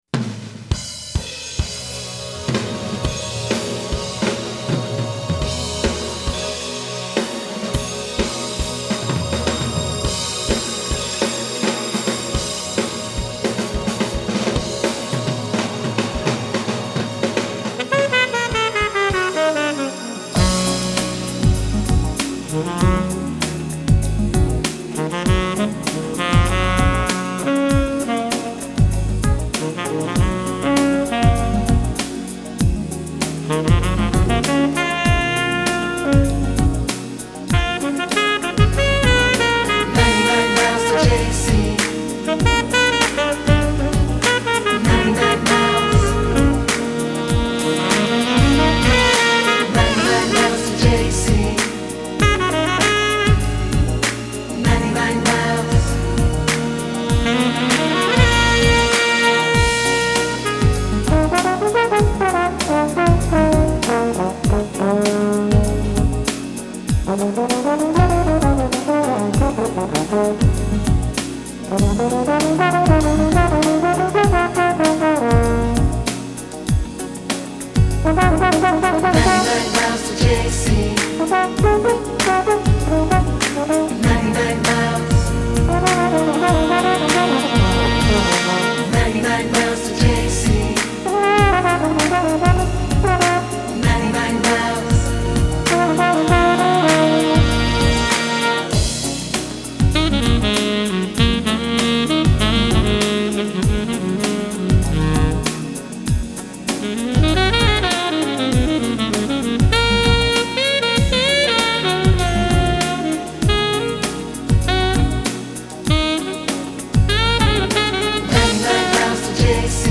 Genre : Funk